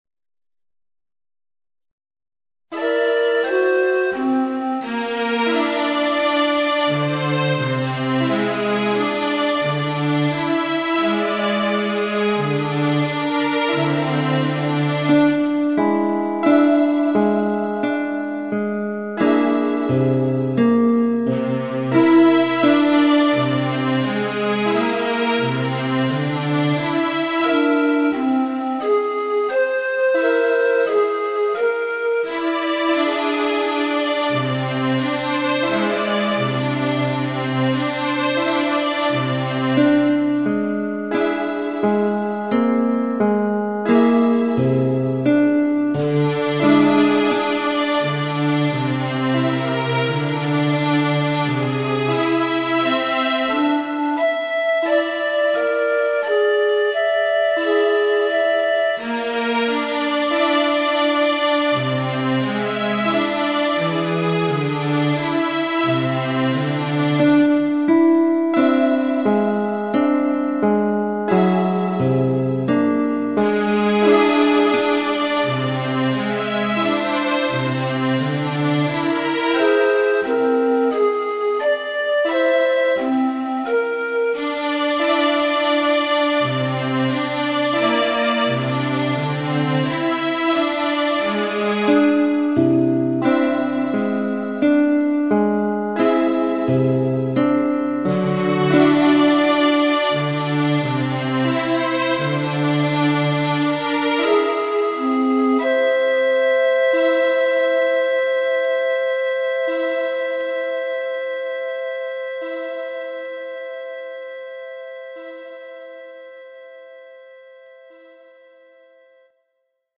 So the next thing we’ll hear will be the two troponins again, but this time using a reduced scale of 9 tones (instead of 20), and set in a pentatonic scale.